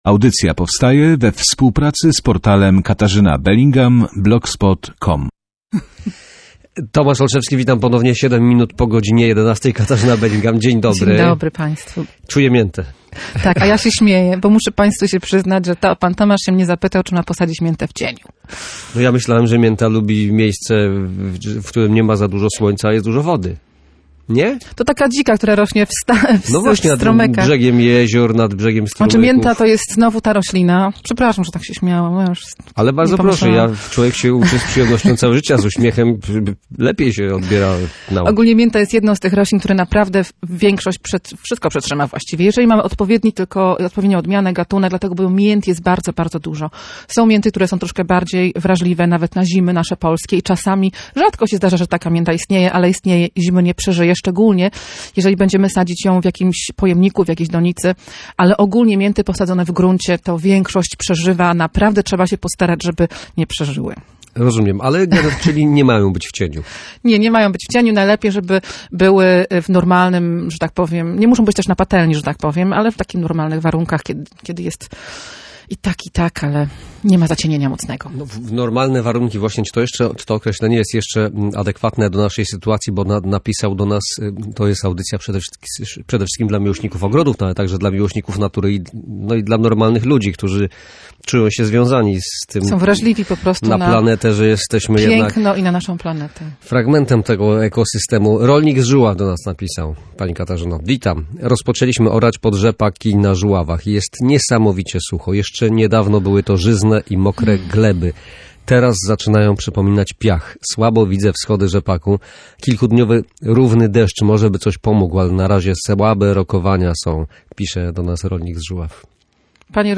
Gościem